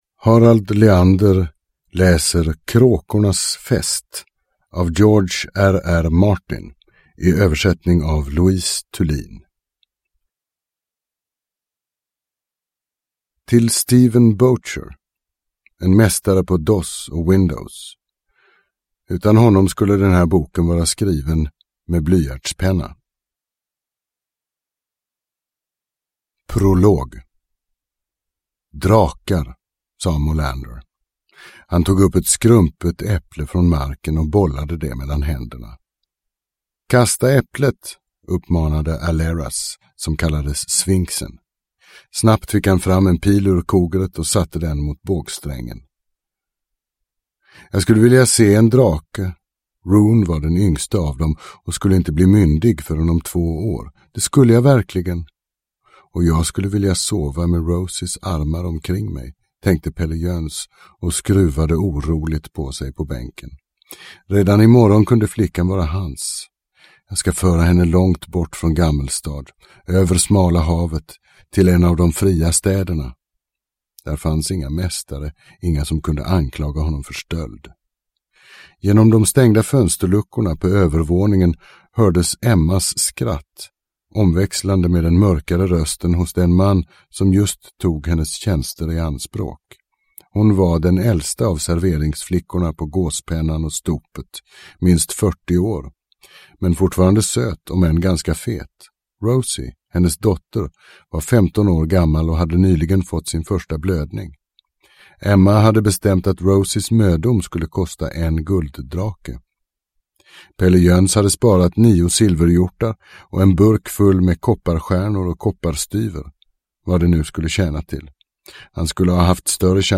Game of thrones - Kråkornas fest – Ljudbok – Laddas ner